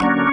键盘 " 风琴01
44khz 16位立体声，无波块。
Tag: 键盘 器官 DB33